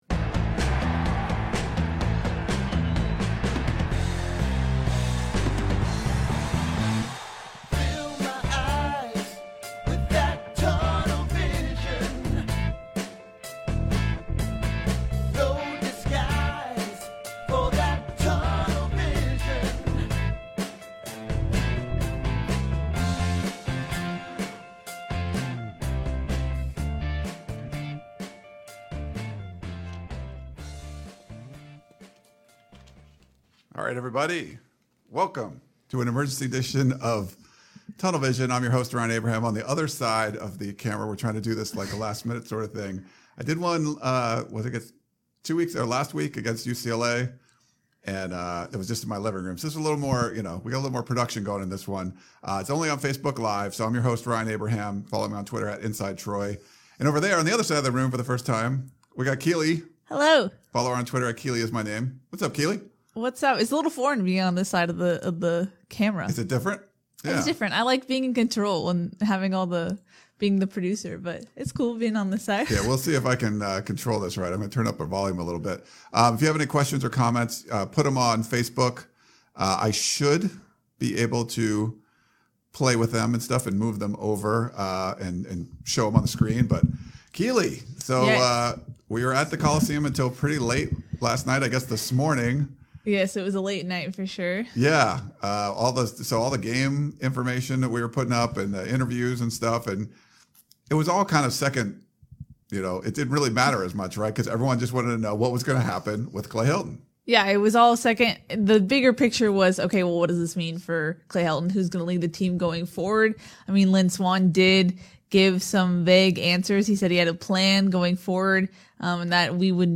This show originally aired on Facebook LIVE in video form.